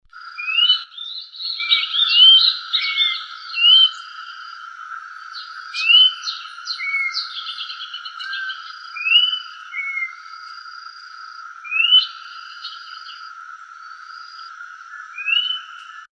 Matico (Icterus croconotus)
Nombre en inglés: Orange-backed Troupial
Fase de la vida: Adulto
Localidad o área protegida: Reserva Ecológica Costanera Sur (RECS)
Condición: Silvestre
Certeza: Vocalización Grabada